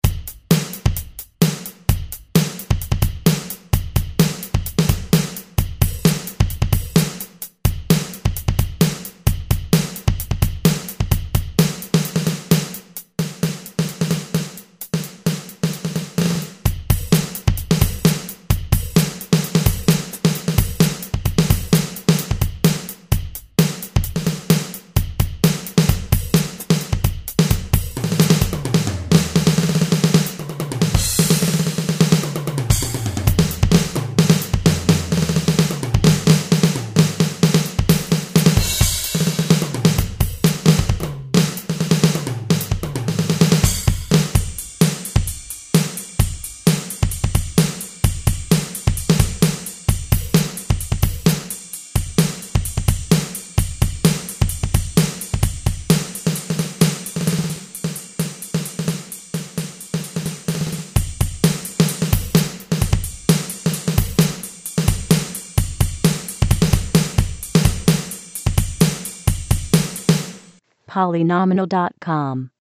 Synthesis: PCM rompler
demo standard kit 1